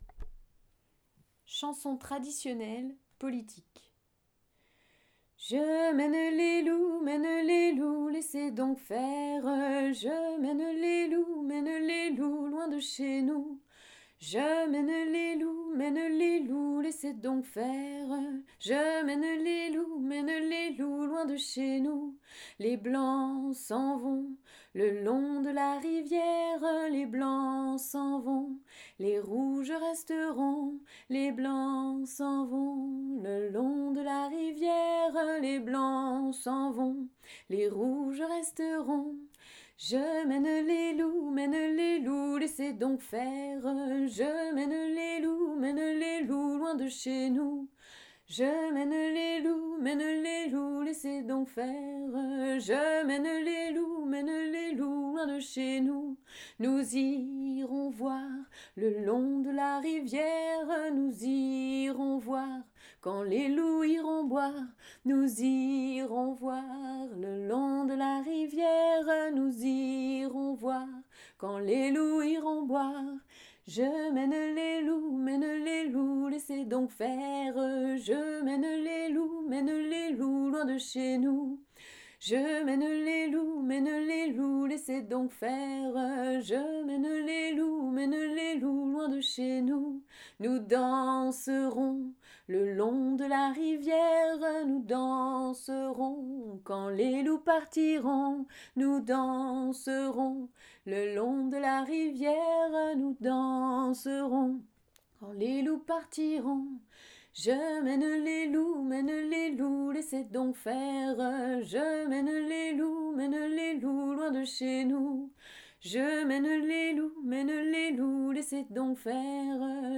La forme la plus commune est celle d’une bourrée à deux temps.
Parole en Tsarollais-Brionnais issue des collectages :